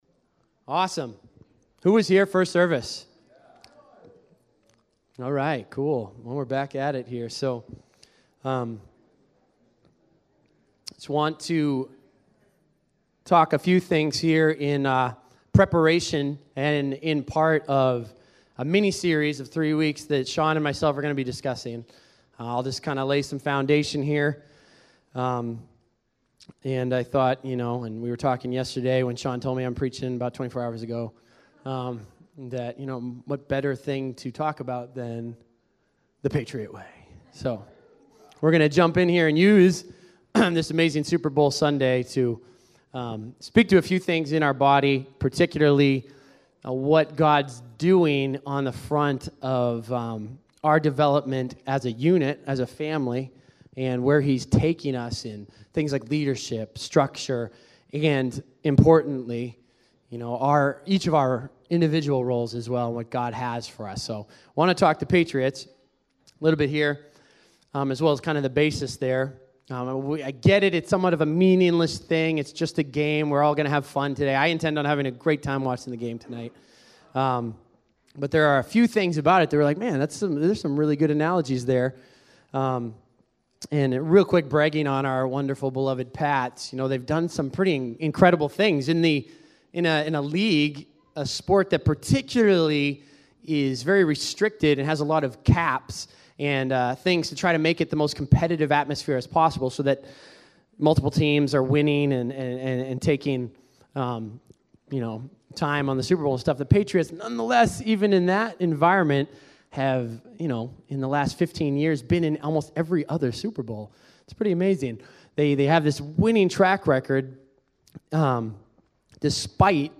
2017-02-05-second-service-sermon.mp3